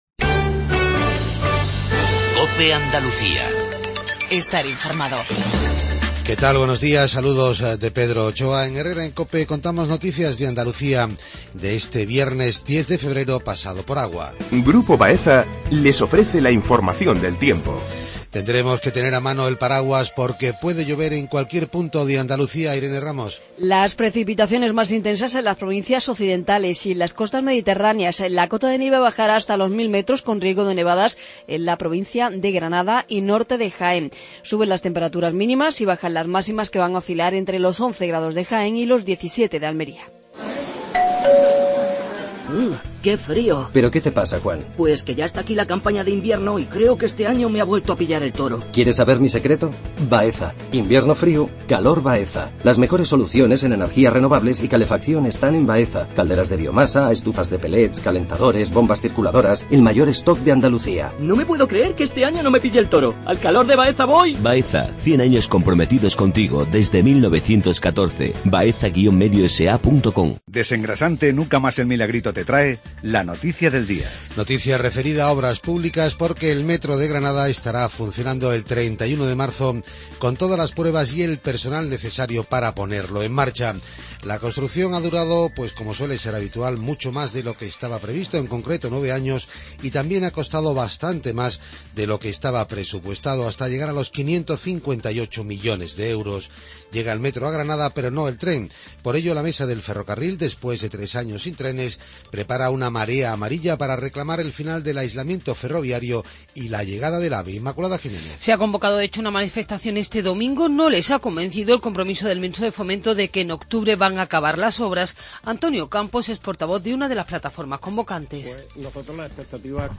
INFORMATIVO REGIONAL/LOCAL MATINAL 7:50